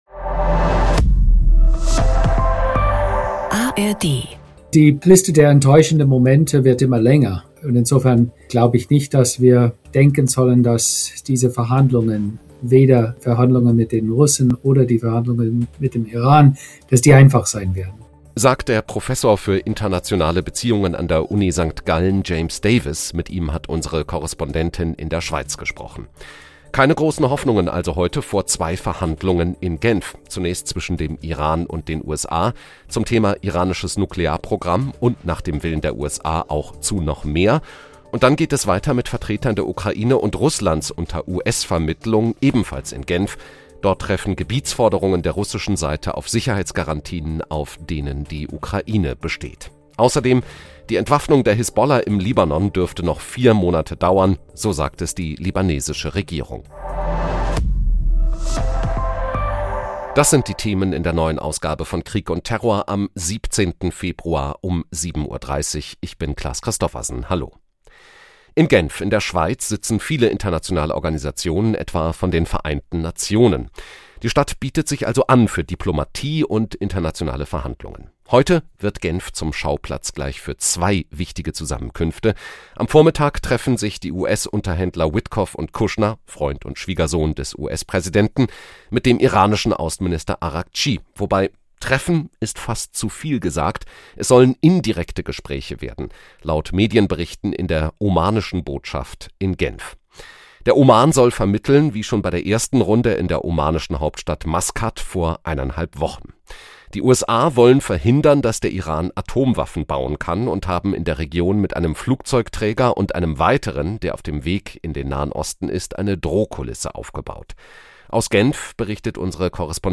Reportage.│